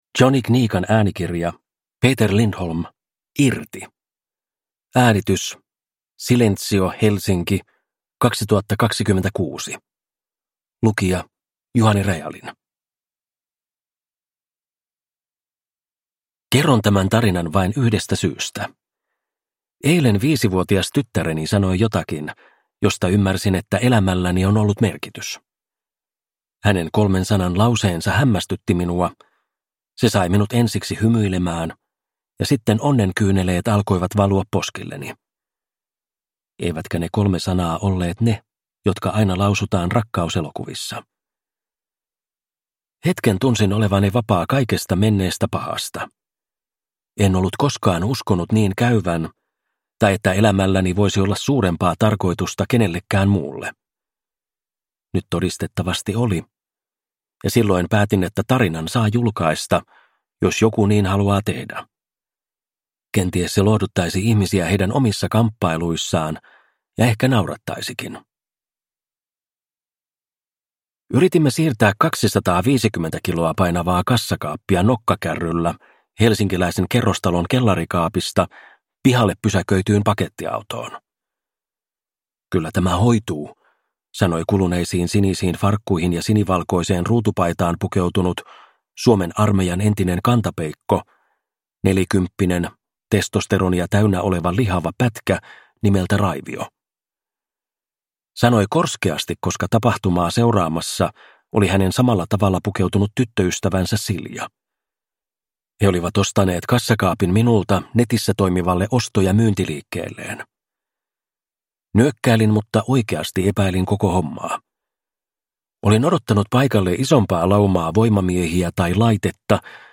Irti (ljudbok